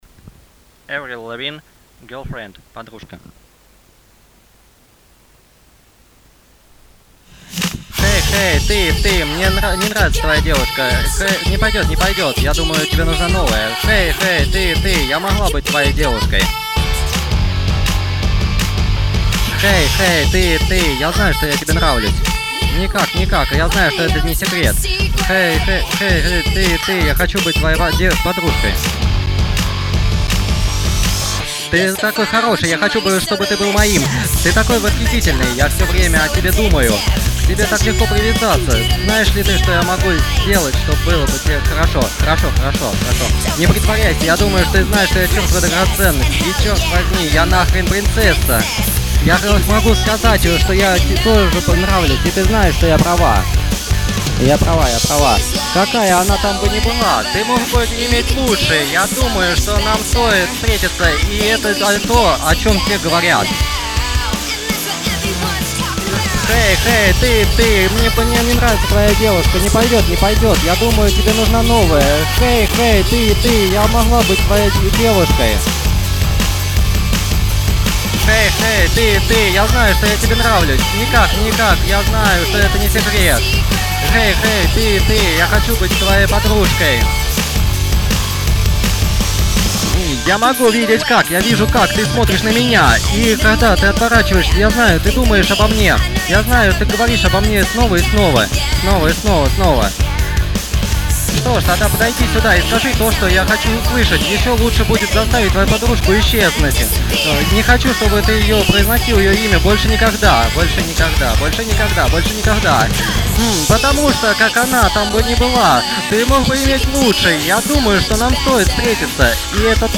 Перевод озвучен в домашних условиях ...